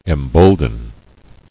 [em·bold·en]